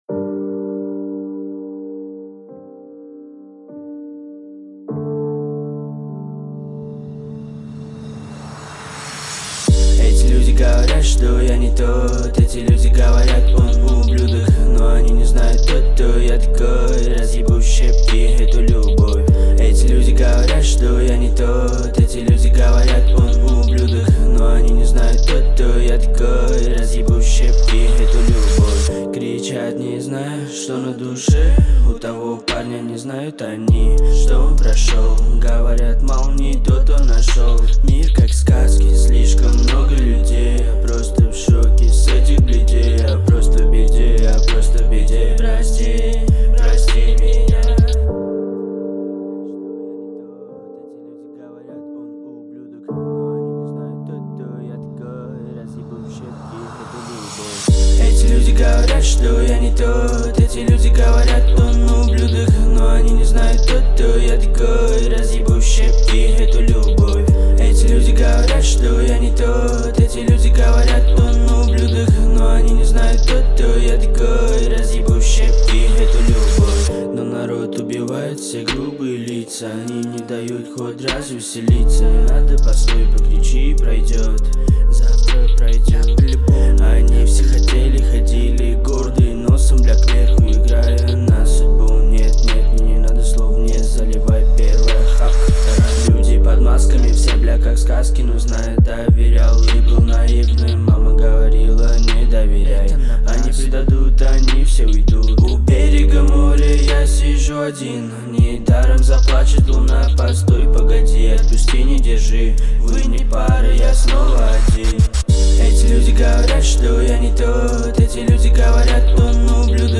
это эмоциональная композиция в жанре поп-рок